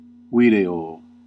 Ääntäminen
Vaihtoehtoiset kirjoitusmuodot not noit noyt Synonyymit banknote add (slangi) buck listen line use bill utility gloss enroll betoken make a note Ääntäminen US : IPA : /noʊt/ UK : IPA : /nəʊt/ Lyhenteet ja supistumat (laki) n.